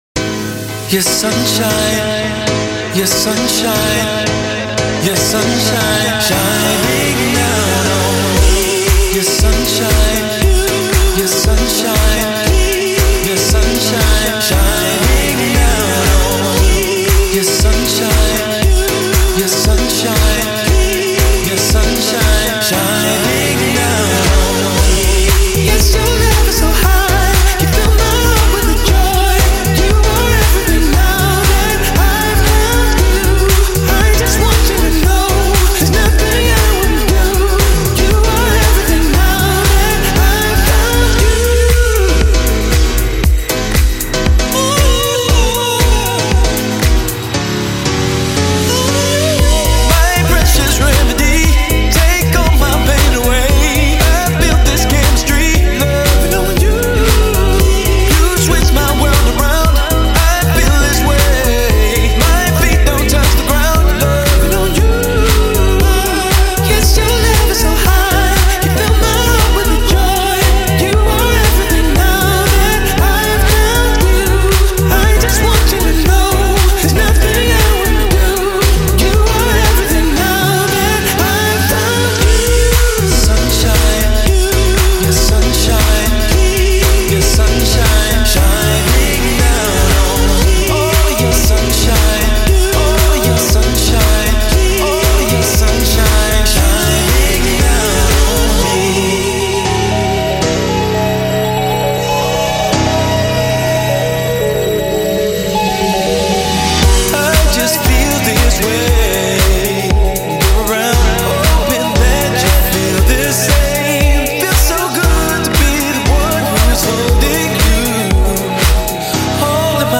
open-format diversity
EDM mix